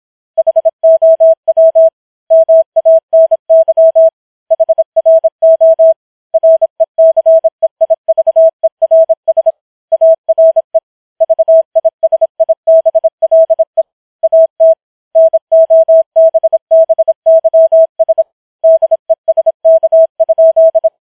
Morse challenge